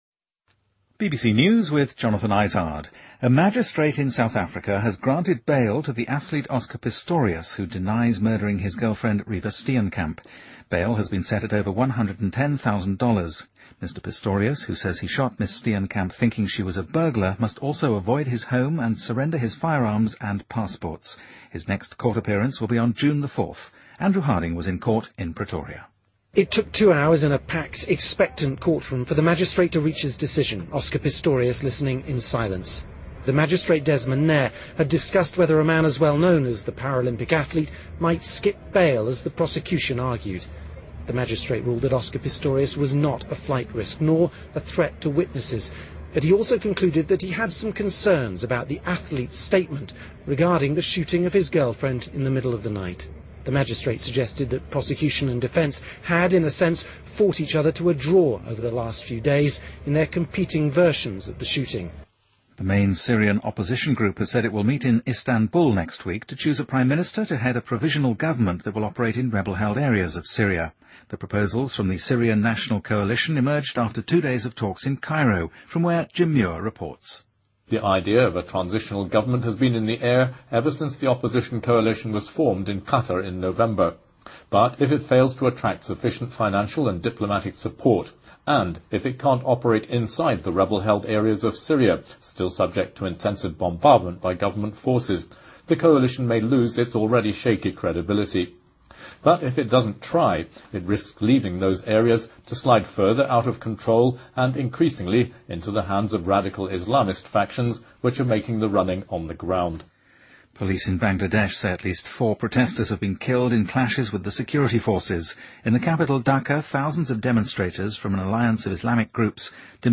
BBC news,被美国家庭收养的俄罗斯男孩死亡一事引起更激烈争论